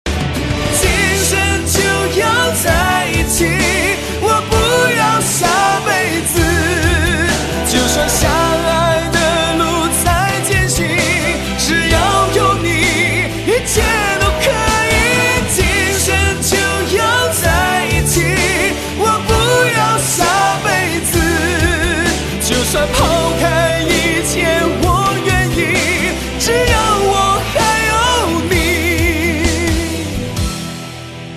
M4R铃声, MP3铃声, 华语歌曲 84 首发日期：2018-05-14 13:35 星期一